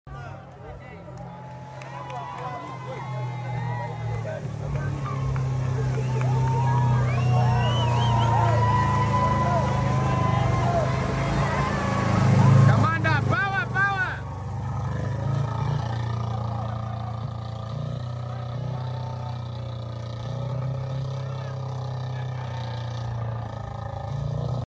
The famously fearless Subaru Leone sound effects free download
The famously fearless Subaru Leone razed through the mud like a beast unleashed — kicking up chaos, carving its legacy, and proving once again why legends never die!”